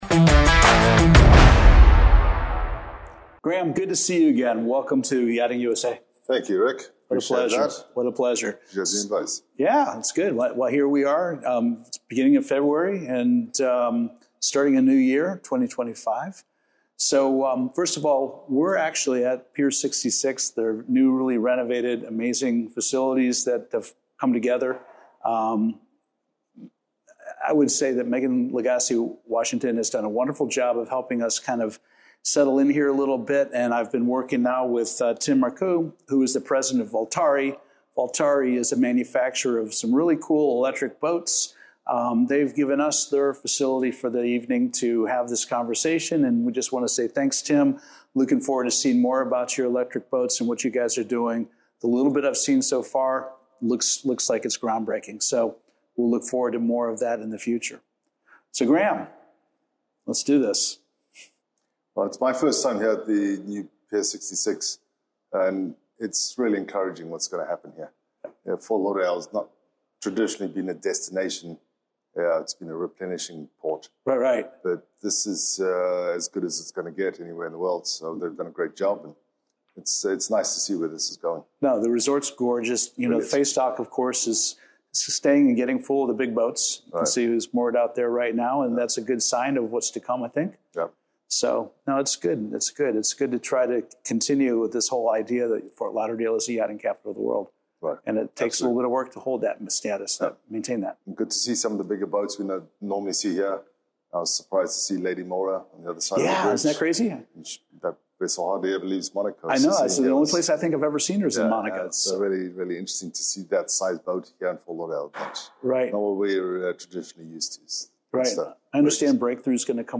Yachting Channel
In this engaging conversation